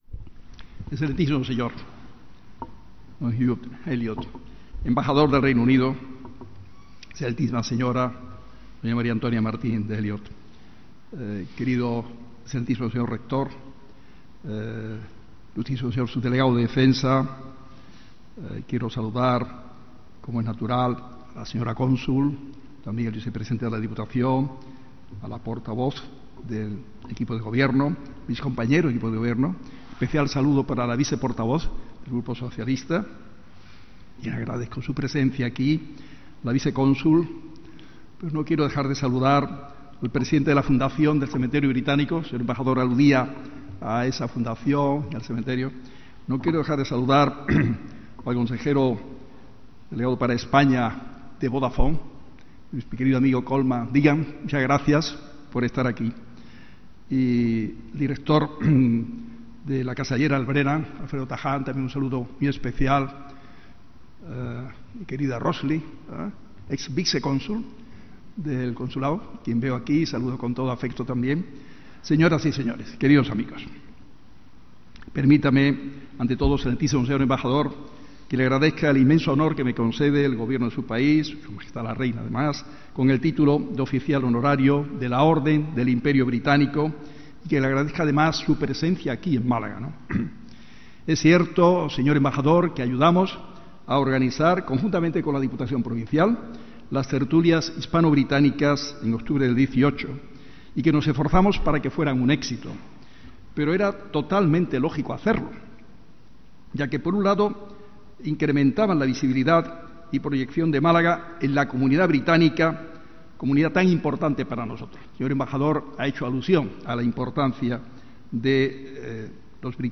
El acto se ha celebrado esta tarde a partir de las 18.00 horas en el Salón de los Espejos
Consulta el audio del DISCURSO DEL ALCALDE
Discurso-Alcalde-medalla-Reino-Unido.mp3